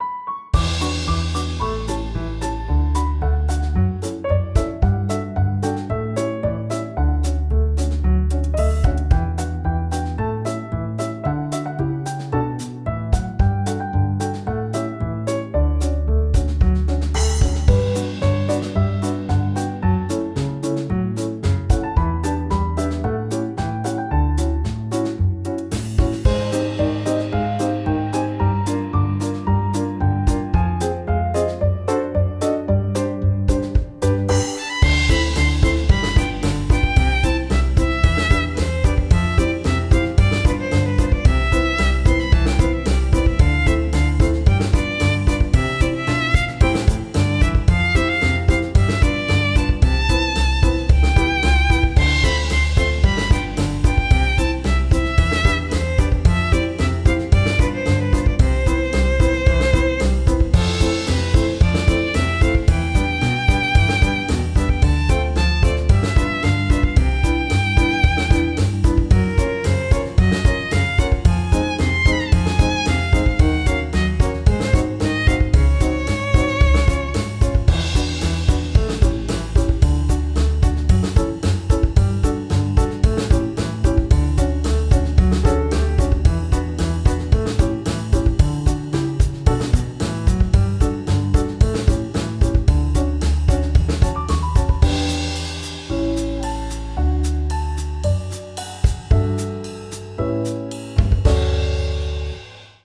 カフェっぽい感じ。